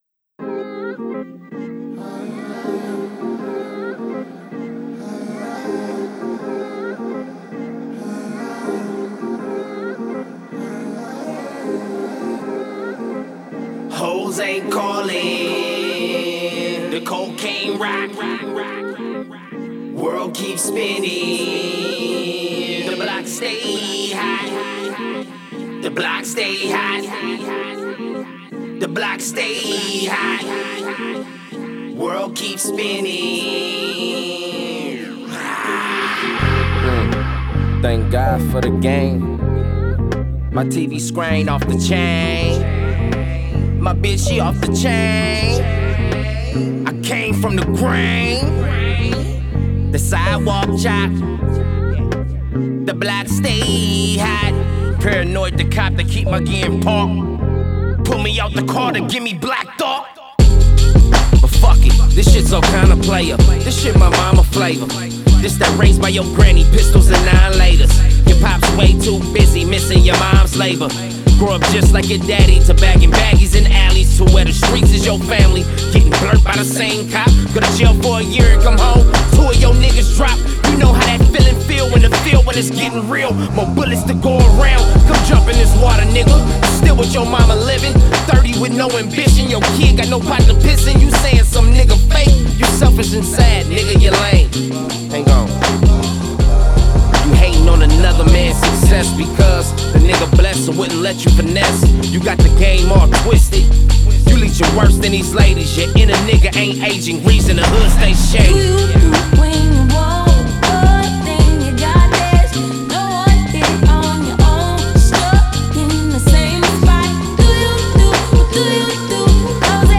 6. SOUL SAMPLE DRIVEN